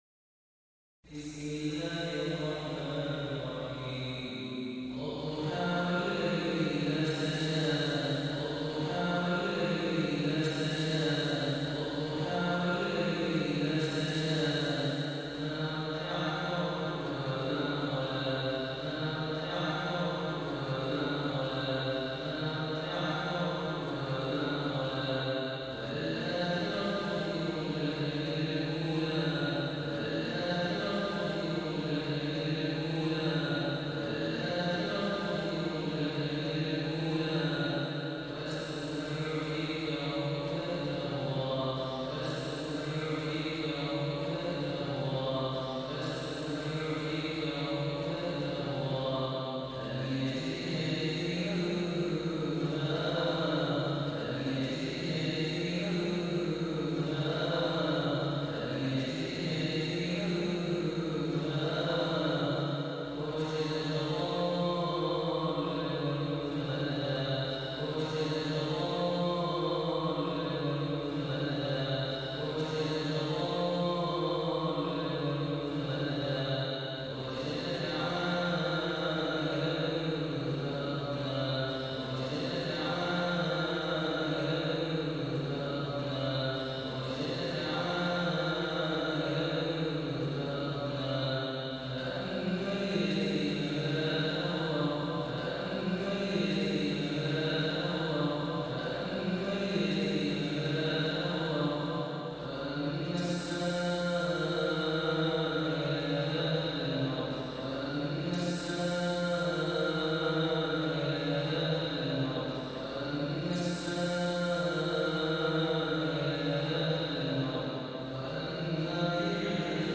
The Holy Quran recitation for Famous readers to listen and download